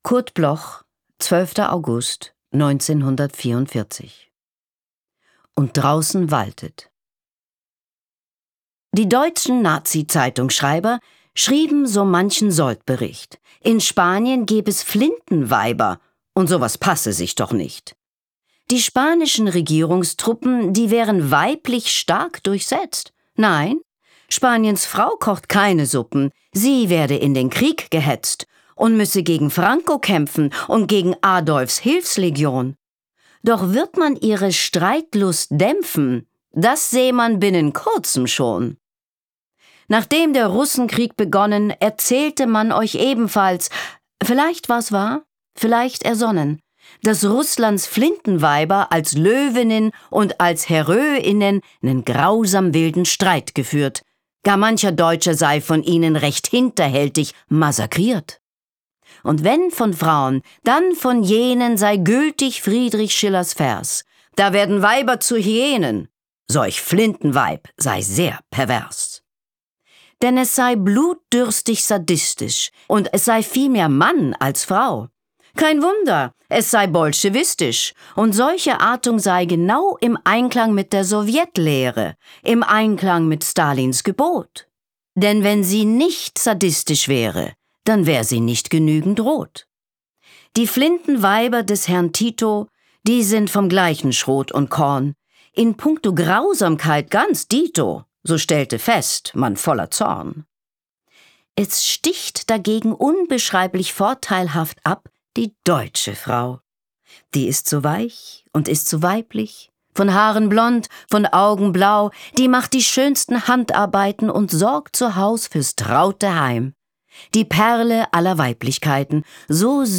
Leslie Malton (* 1958) is an German-American actress as well as a narrator of audiobooks and radio plays.
Recording: speak low, Berlin · Editing: Kristen & Schmidt, Wiesbaden